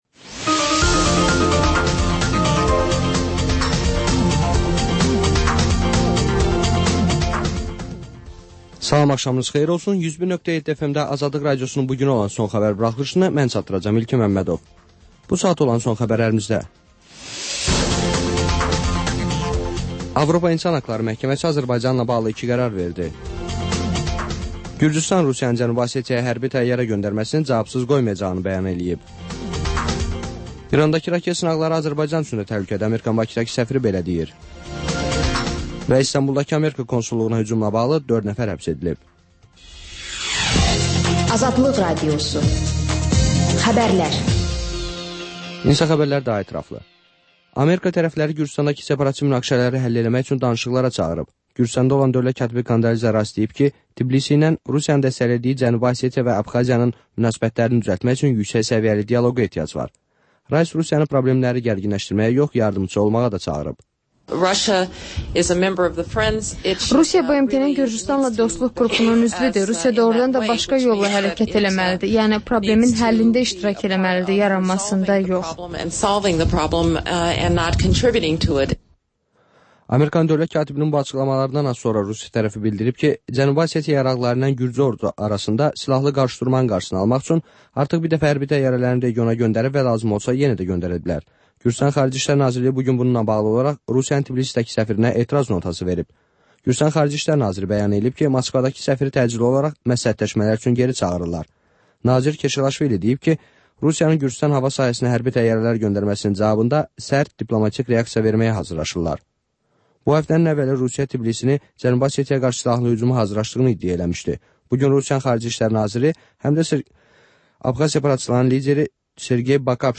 Xəbərlər, REP-TIME: Gənclərin musiqi verilişi